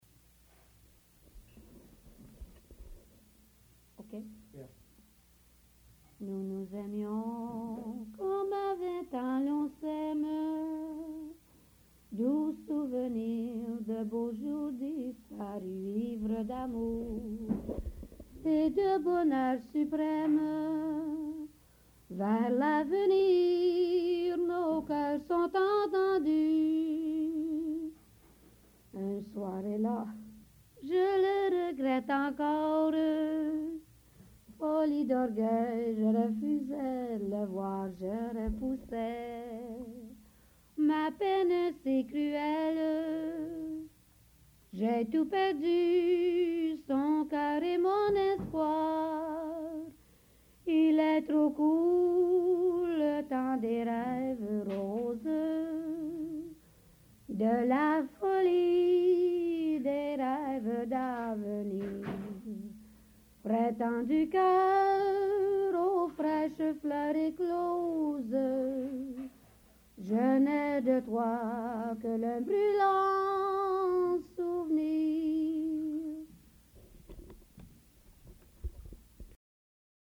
Folk Songs, French--New England Folk Songs, French--Québec (Province)